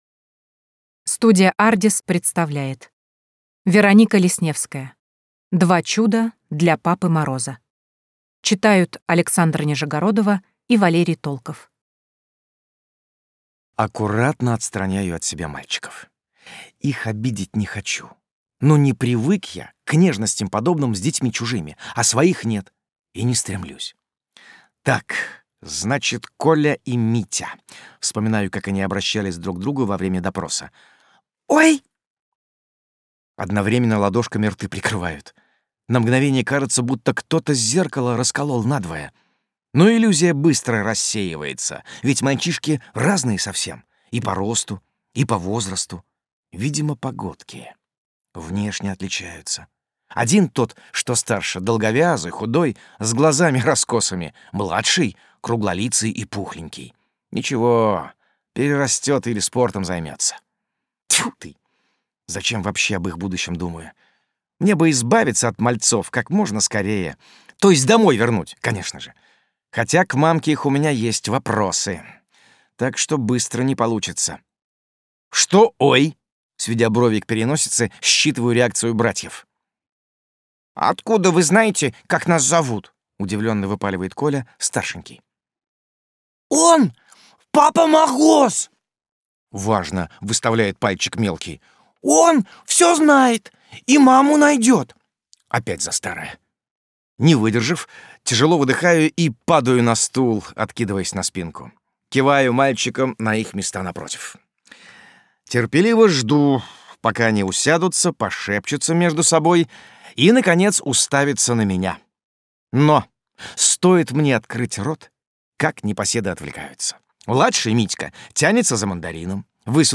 Аудиокнига Два чуда для Папы Мороза | Библиотека аудиокниг